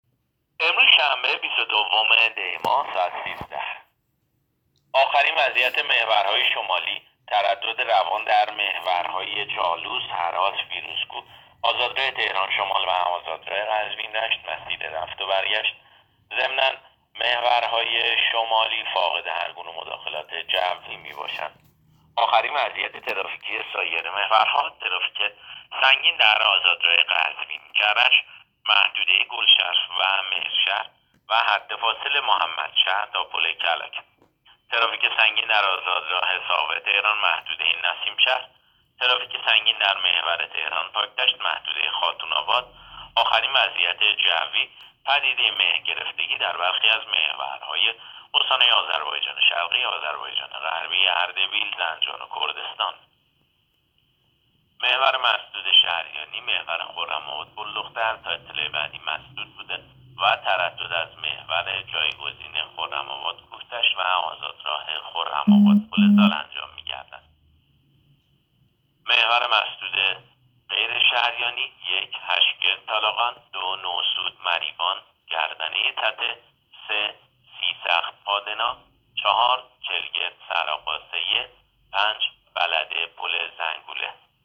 گزارش رادیو اینترنتی از آخرین وضعیت ترافیکی جاده‌ها تا ساعت ۱۳ بیست‌ودوم دی؛